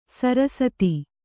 ♪ sarasati